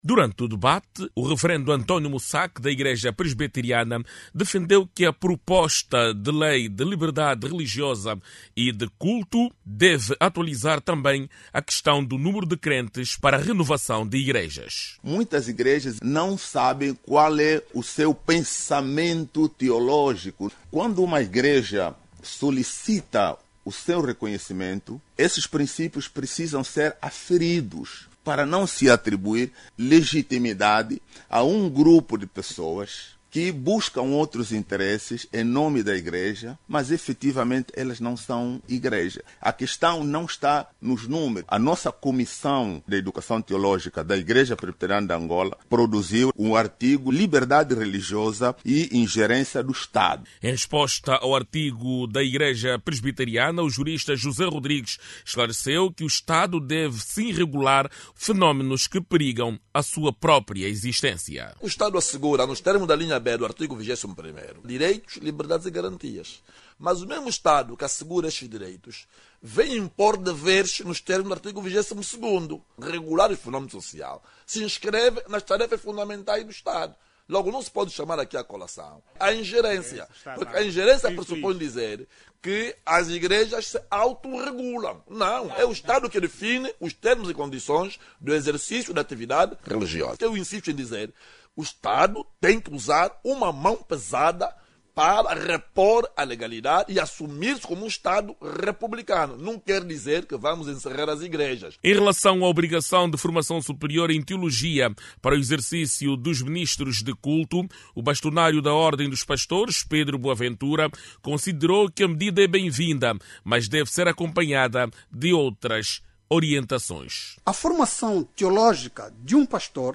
Hoje, quinta-feira(10), o debate Angola em Directo, analisou a Proposta de Lei da Liberdade Religiosa e de Culto. As igrejas são unânimes em afirmar que o diploma é bem-vindo, apesar de existirem ainda dúvidas. Clique no áudio abaixo e ouça a reportagem